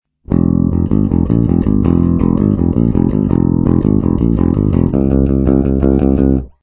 Bajo eléctrico tocado con púa
Esto aporta más ataque, más definición y una respuesta diferente en el sonido.
bajo-electrico-tocado-con-pua.mp3